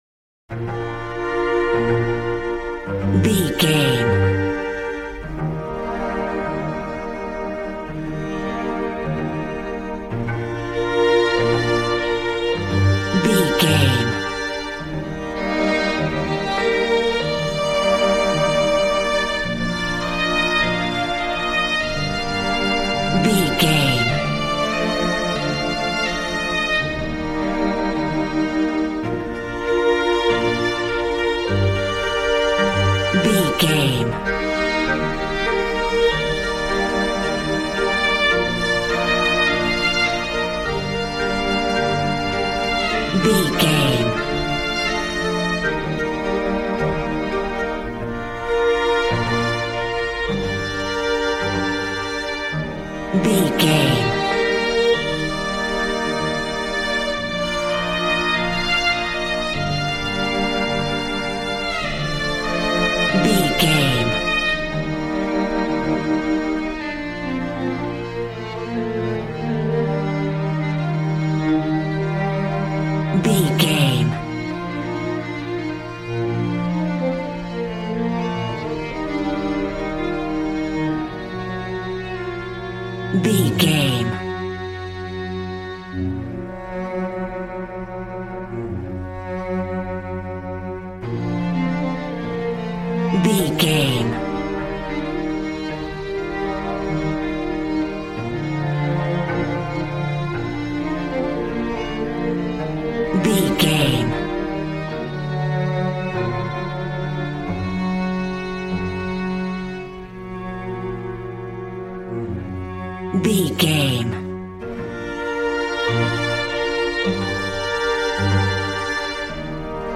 Ionian/Major
B♭
regal
cello
violin
brass